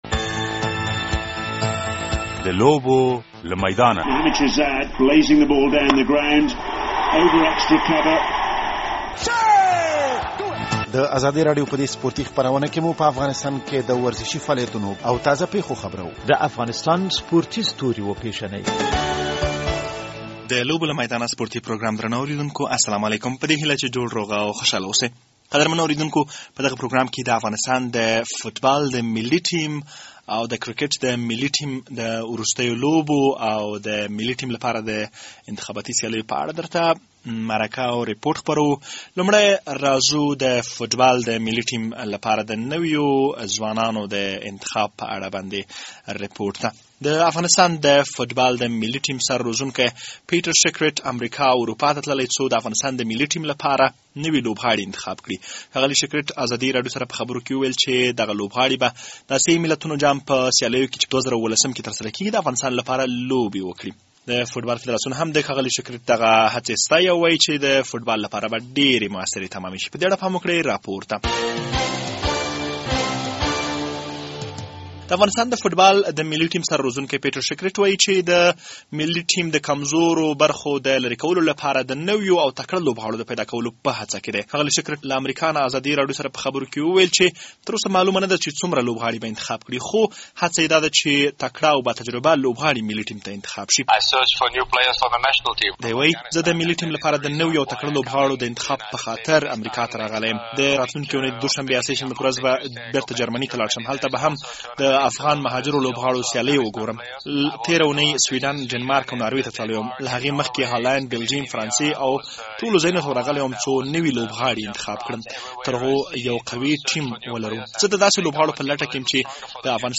د لوبو له میدانه سپورټي پروګرام خپرېدو ته چمتو دی.